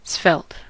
svelte: Wikimedia Commons US English Pronunciations
En-us-svelte.WAV